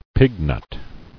[pig·nut]